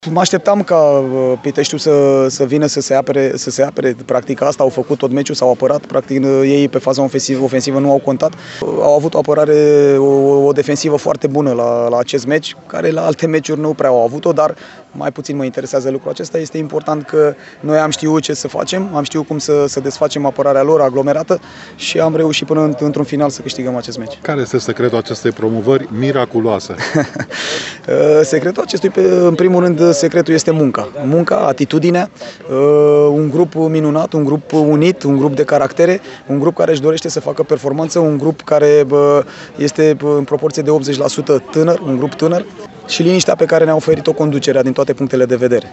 a vorbit după meci despre jocul defensiv al adversarilor, dar și despre bucuria rezultatului ce duce Clinceniul în Liga I: